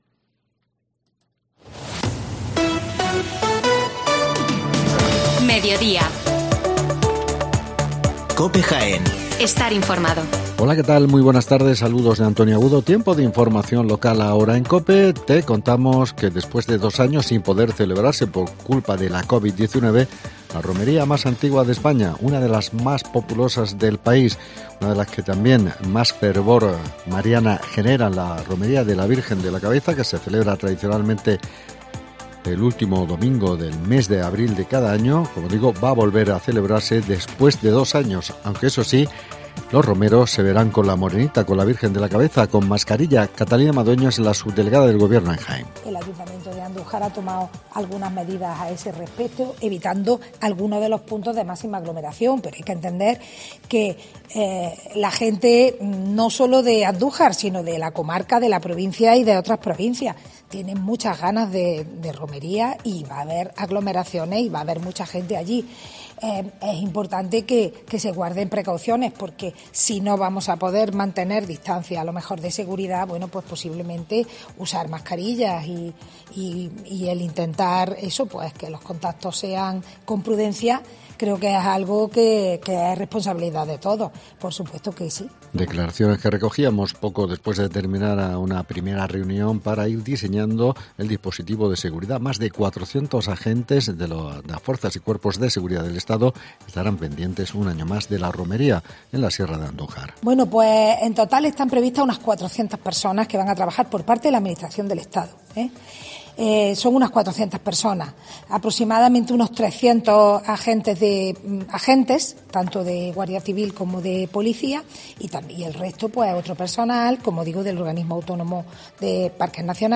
MEDIODÍA JAÉN Las noticias locales